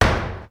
Percussive FX 12 ZG